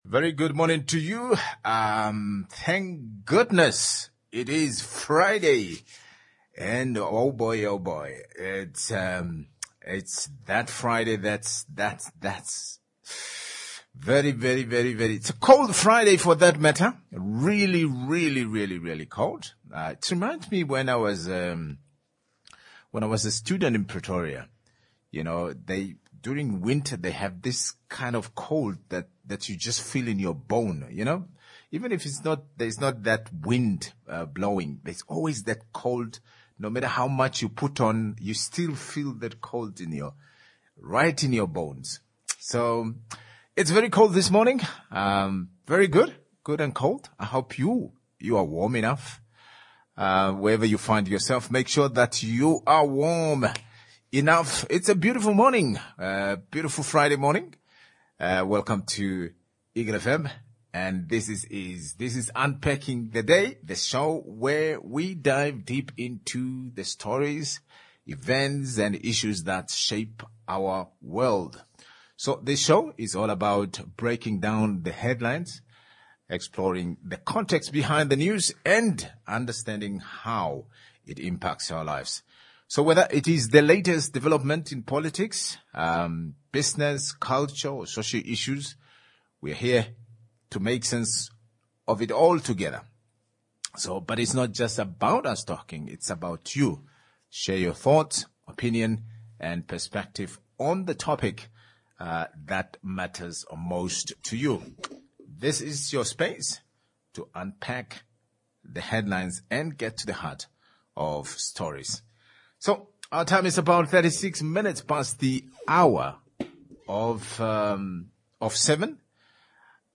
1. Namibia's Debt Crisis and Proposed Reforms, interviewed Hon. Inna Hengari, MP 2. Government Approves Pension-Backed Home Loan Scheme for Civil Servants.